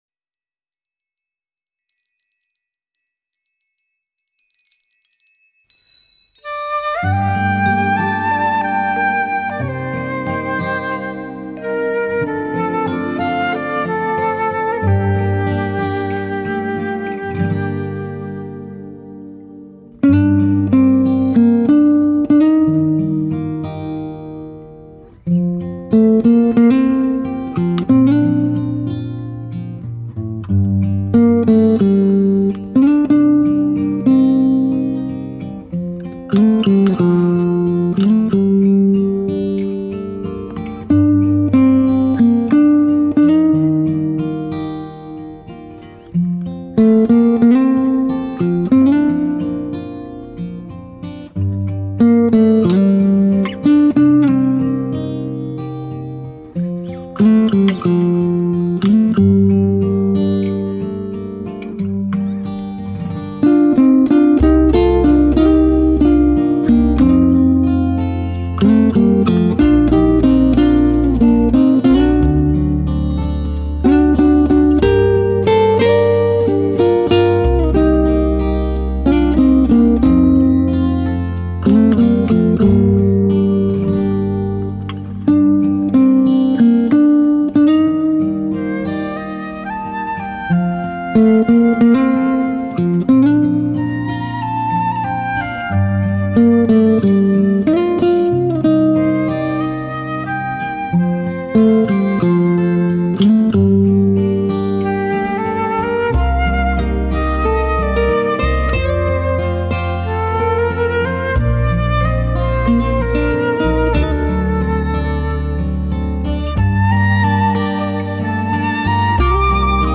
Nhạc Thư Giãn